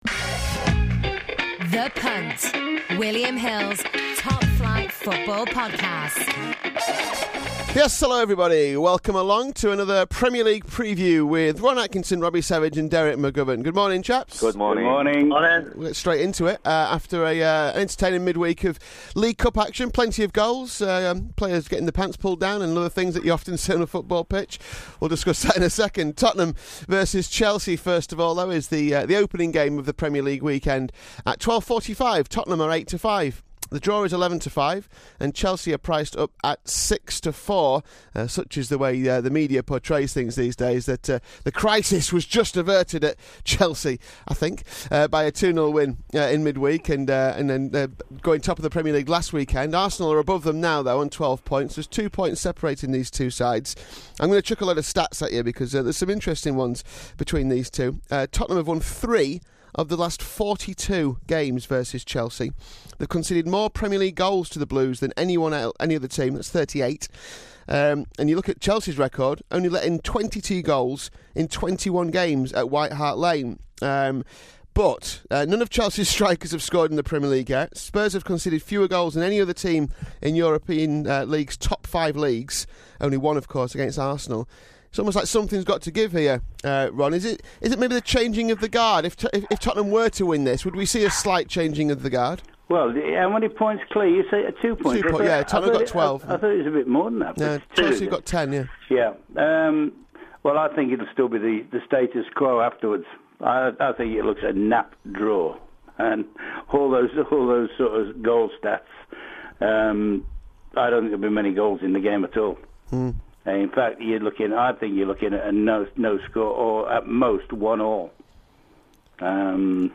The guys put up bets in all the matches and there is the usual mix of banter and debate.